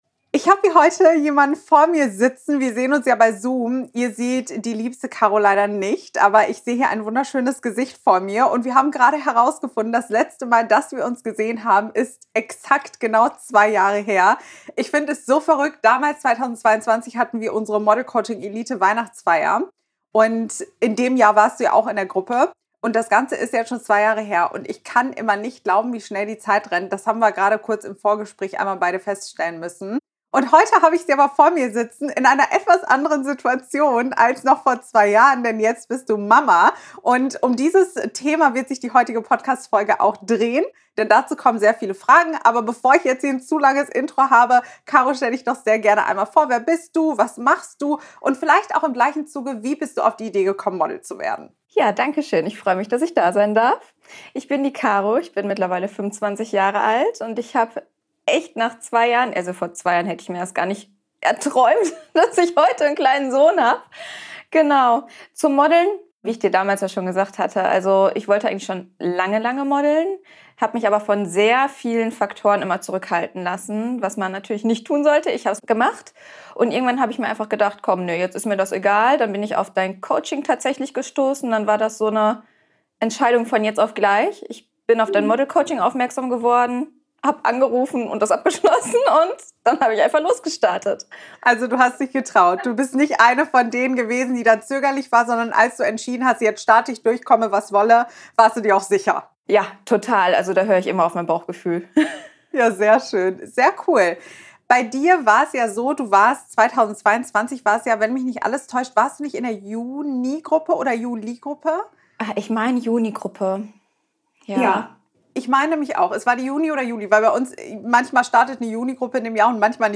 #261: Schwangerschaft und Muttersein, das Ende der Modelkarriere? - Interview